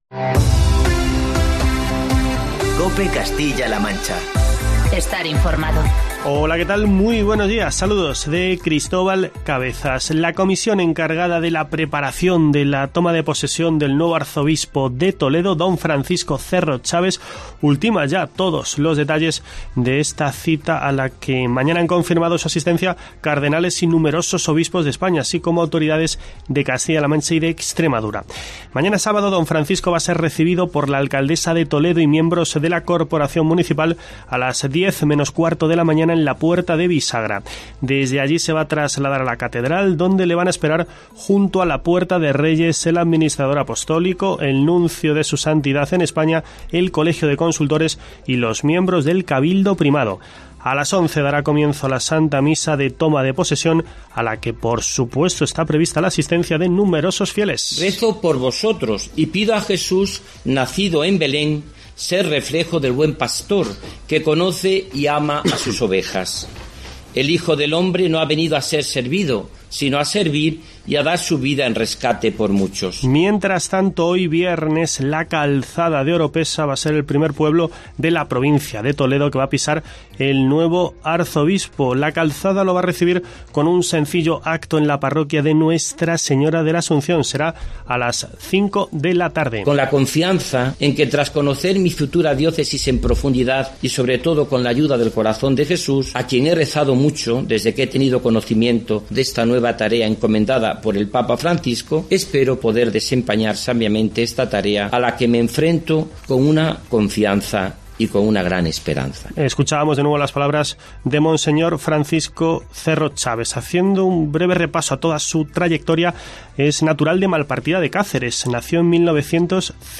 Escucha en la parte superior de esta noticia el informativo de COPE Castilla-La Mancha en el que te contamos todos los detalles sobre la toma de posesión este sábado de don Francisco Cerro Chaves como arzobispo de Toledo.